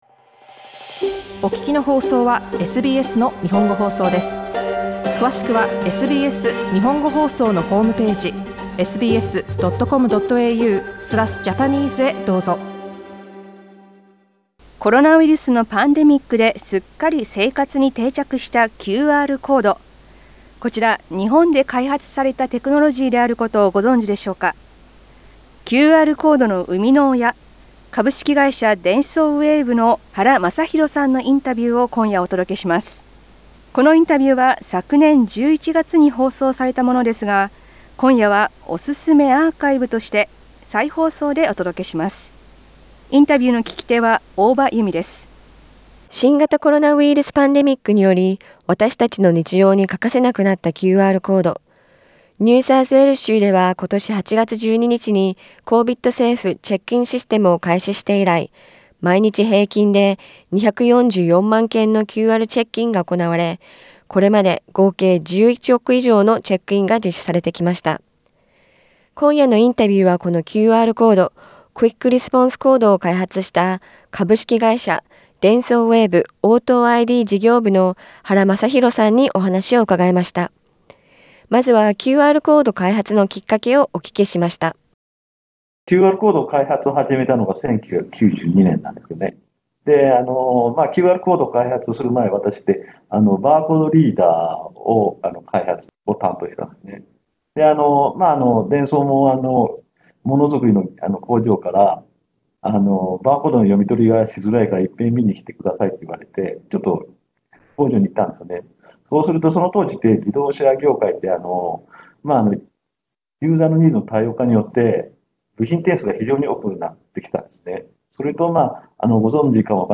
2021年11月に放送されたインタビューを再放送でお届けします。